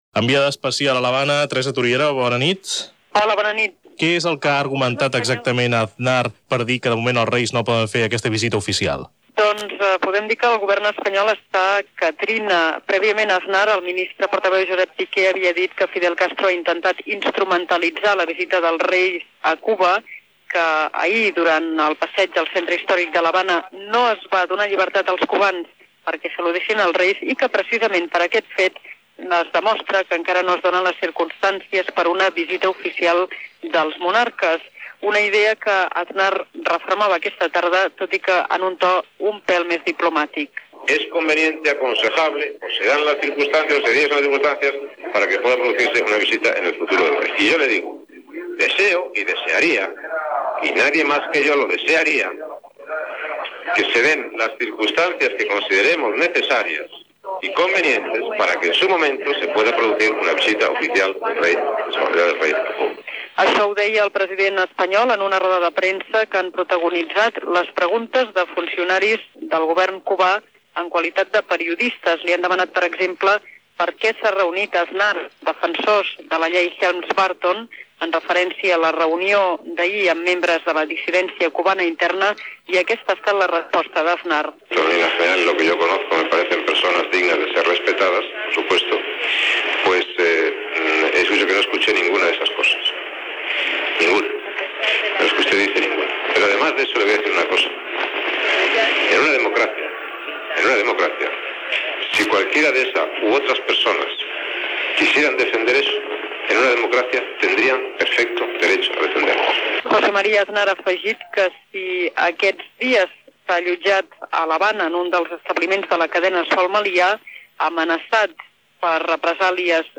Crònica des de l'Havana de la visita del president del govern espanyol José María Aznar i dels reis d'Espanya a l'Havana per participar al la novena Cimera Iberoamericana. Declaracions de José María Aznar
Informatiu